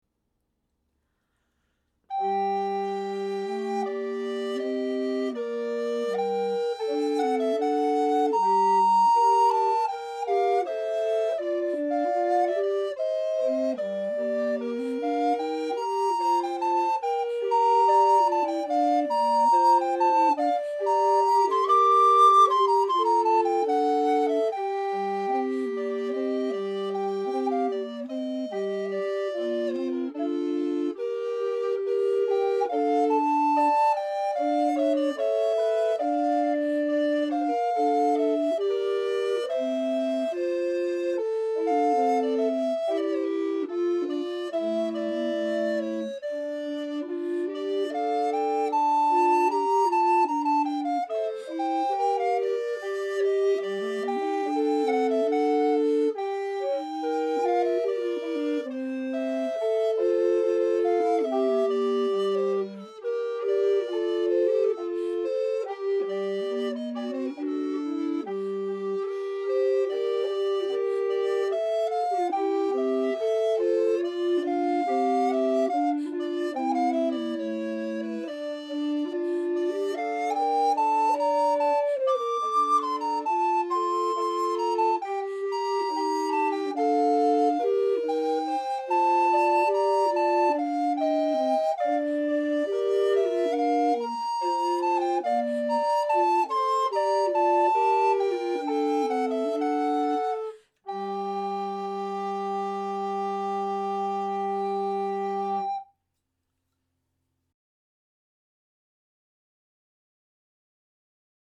Music from the 15th and 16th centuries
recorders
at the Loring-Greenough House, Jamaica Plain